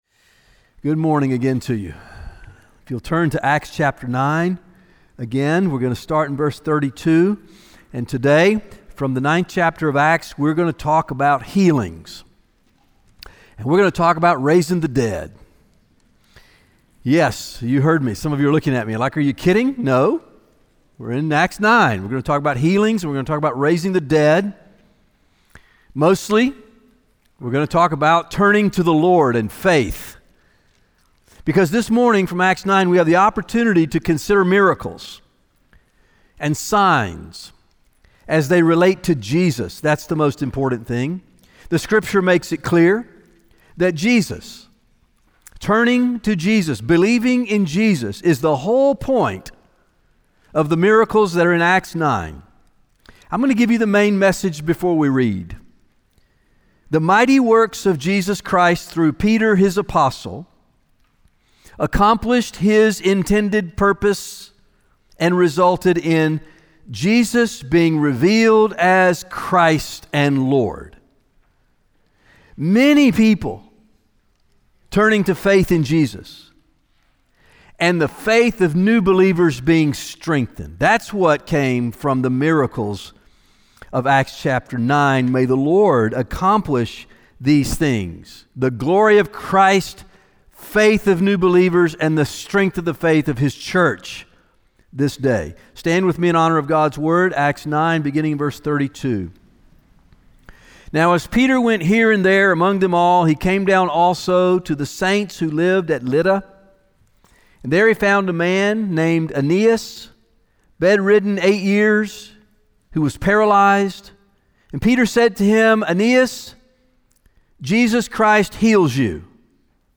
One of the ways that we pursue this mission is by gathering each Sunday for corporate worship, prayer, and biblical teaching.
Sermons